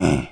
spawners_mobs_mummy_hit.2.ogg